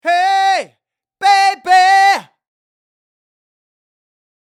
Its character is often direct, loud, and shouted, like when you call ‘hey’ after someone in the street.
Vowels in Overdrive
Hence, ‘EE’, ‘I’, and ‘A’ are altered to ‘EH’.
#110(Male)